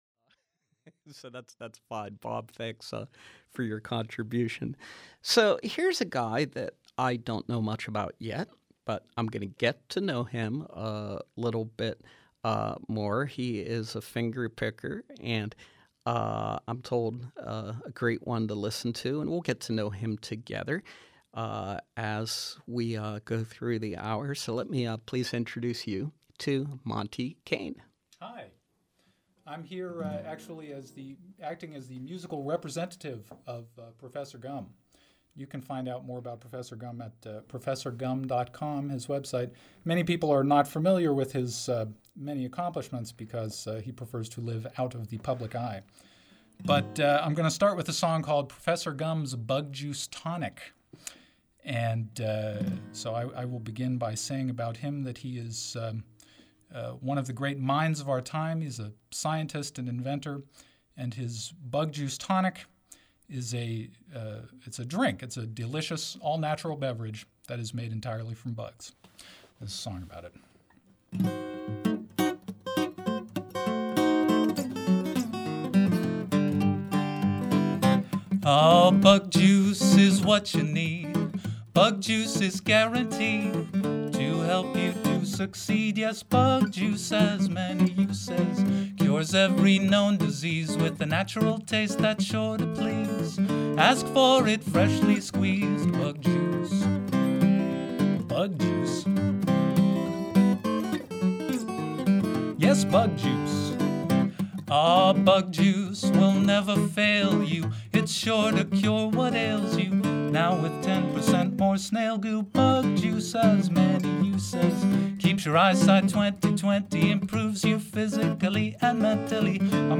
Live music with fingerstyle guitarist